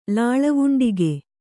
♪ lāḷa vuṇḍige